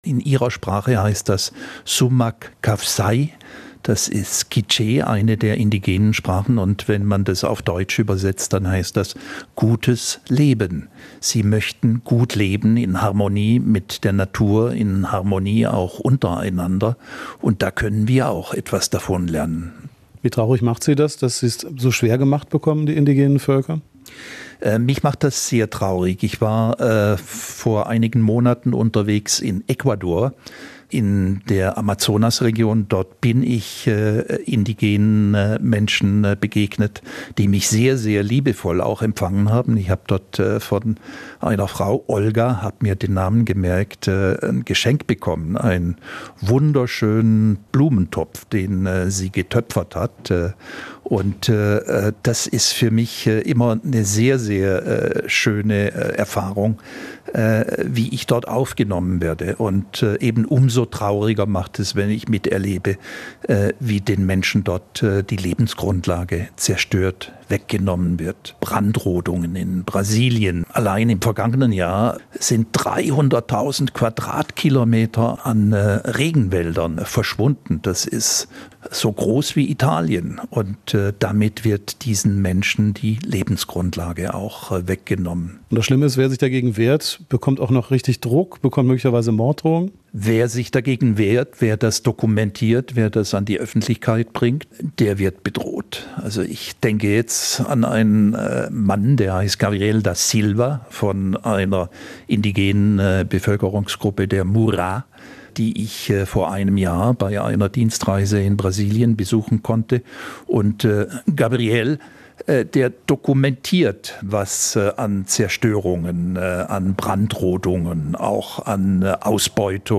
Interview zum Welttag der indigenen Völker